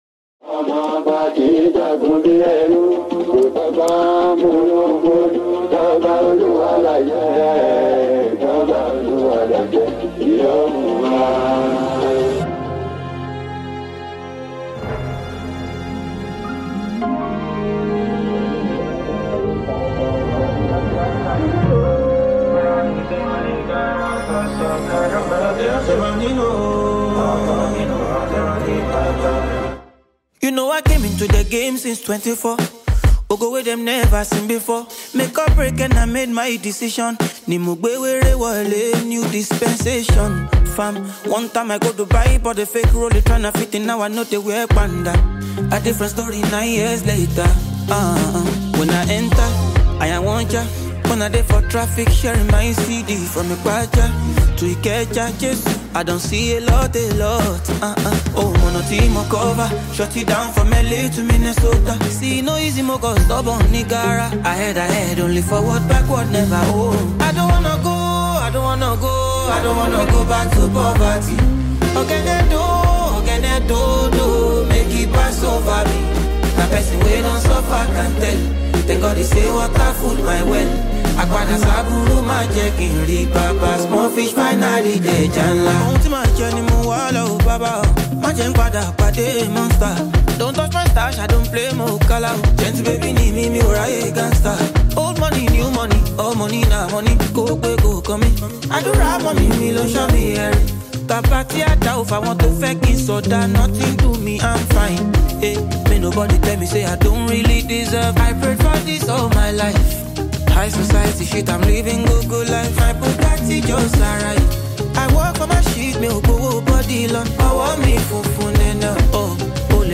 It keeps a steady pace that never feels overwhelming.